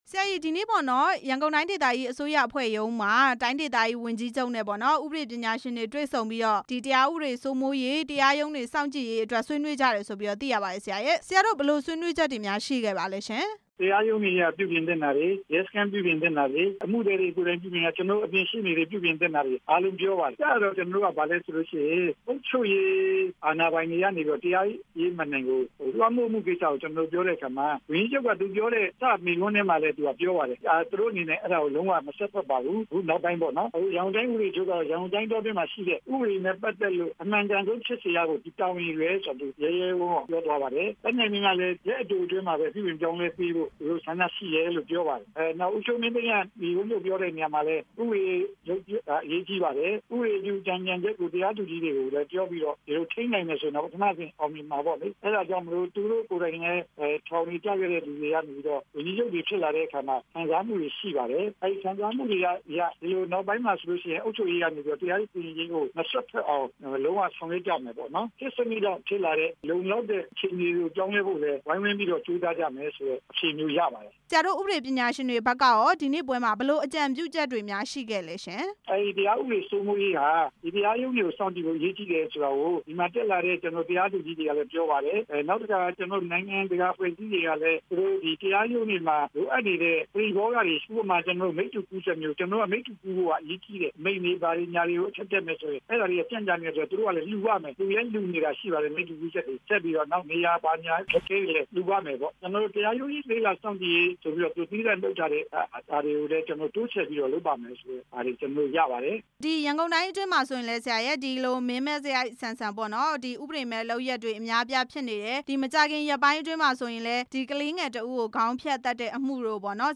တရားဥပဒေ စိုးမိုးရေးနဲ့ တရားရုံးတွေ စောင့်ကြည့်ရေး ကိစ္စ မေးမြန်းချက်